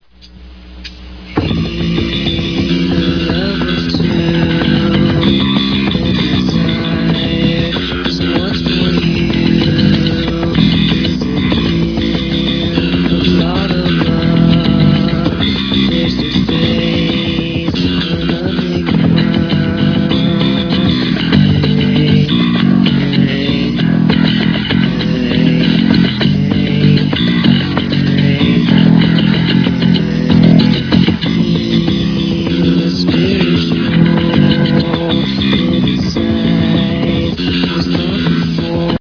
only this only has an un-plugged bass playing